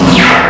assets/ctr/nzportable/nzp/sounds/weapons/raygun/shoot.wav at a21c260aab705f53aee9e935cc0f51c8cc086ef7
shoot.wav